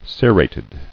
[ce·rat·ed]